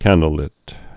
(kăndl-lĭt)